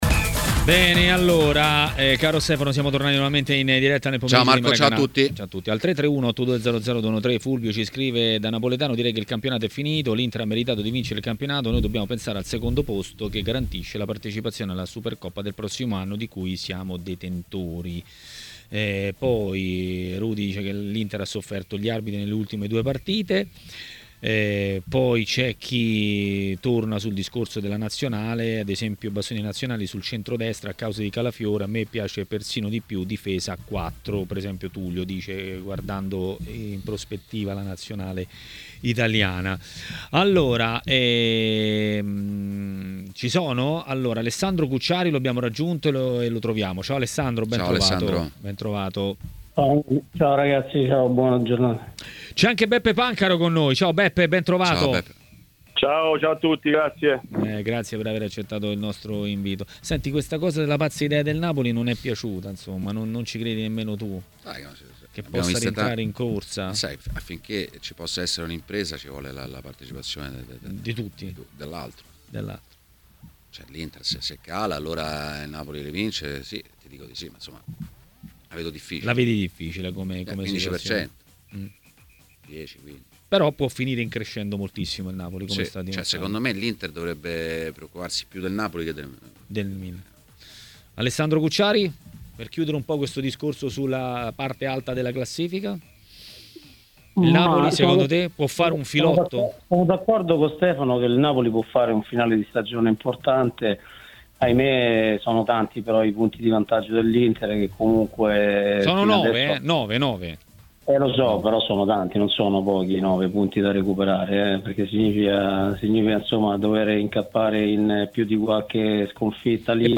L'ex calciatore e tecnico Giuseppe Pancaro ha parlato ai microfoni di TMW Radio, durante Maracanà.